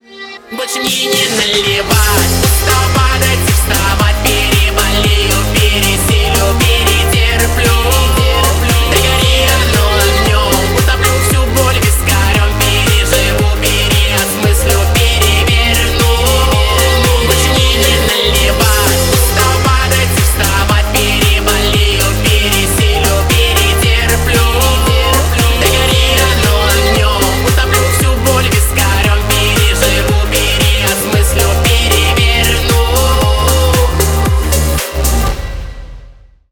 громкие , клубные , поп